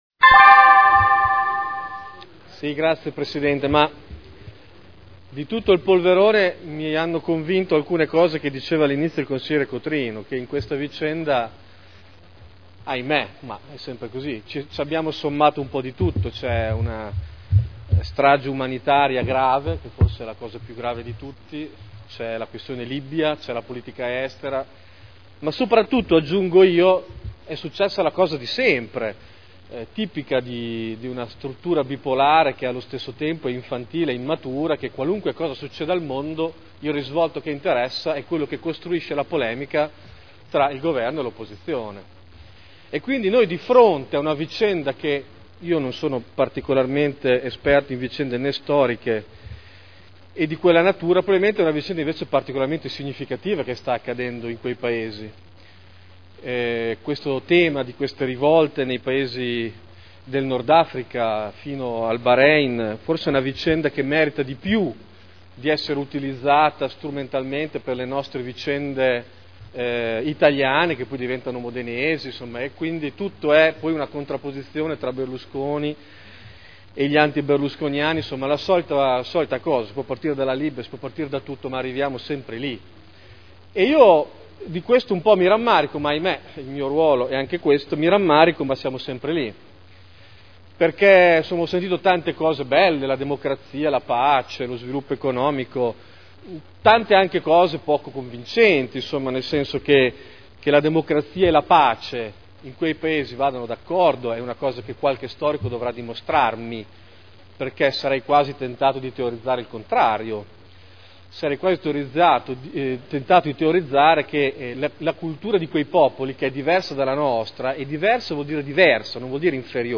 Seduta del 24/02/2011. Interviene sugli Ordini del Giorno riguardanti gli avvenimenti in Libia.